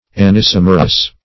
Search Result for " anisomerous" : The Collaborative International Dictionary of English v.0.48: Anisomerous \An`i*som"er*ous\, a. [See Anisomeric .]
anisomerous.mp3